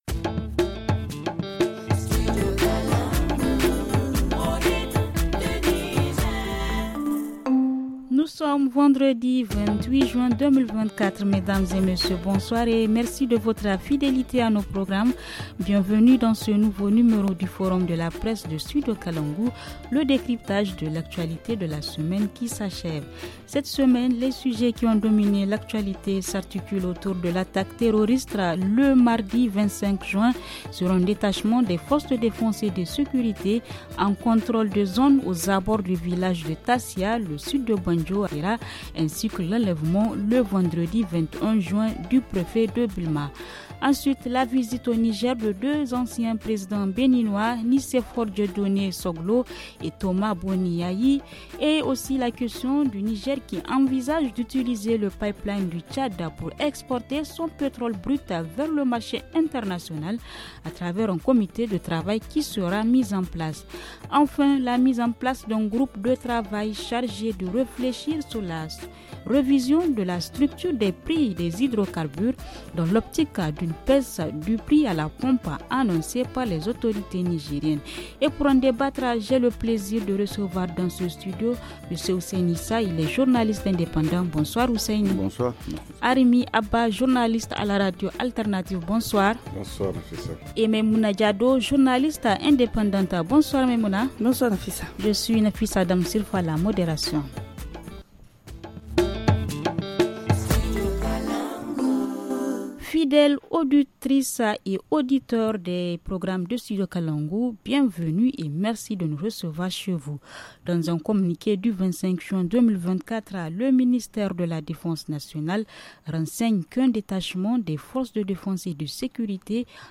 journaliste indépendante